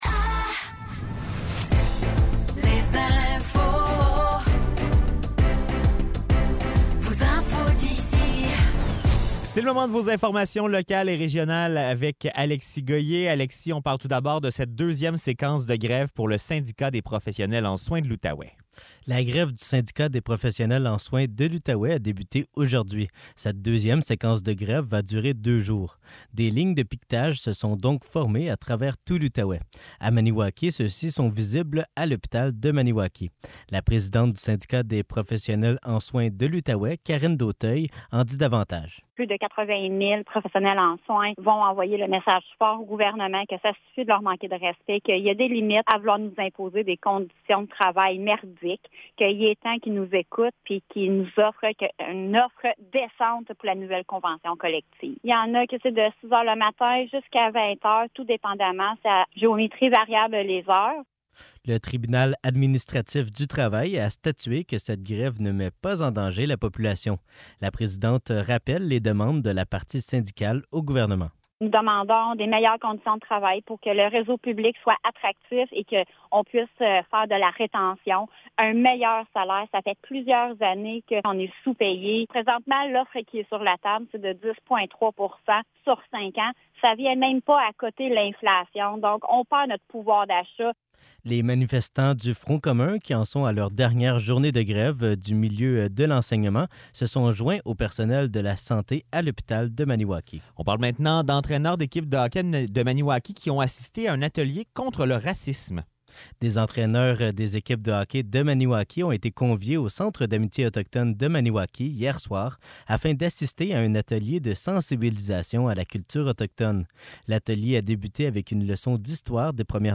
Nouvelles locales - 23 novembre 2023 - 16 h